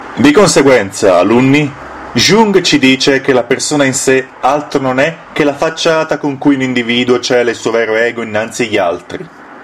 Versatile voice, ready to serve, proper, honest, dutyful, no time-waster.
Sprechprobe: eLearning (Muttersprache):